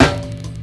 SNARE_BUDDAH.wav